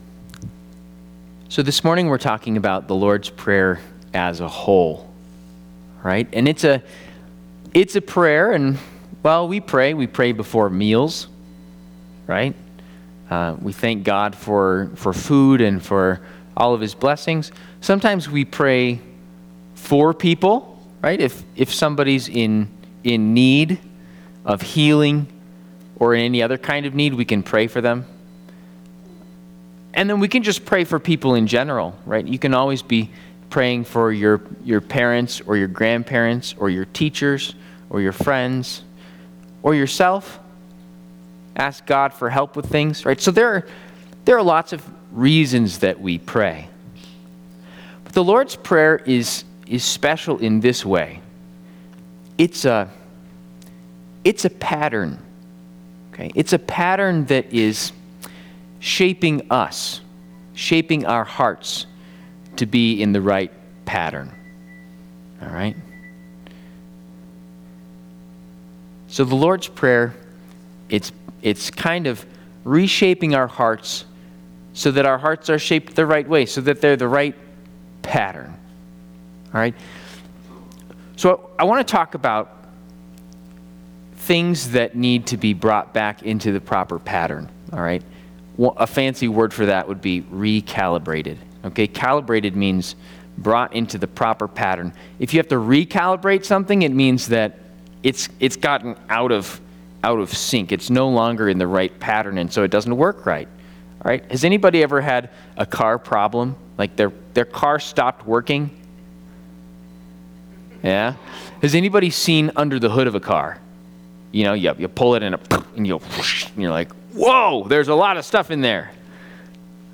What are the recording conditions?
Trinity Lutheran Church, Greeley, Colorado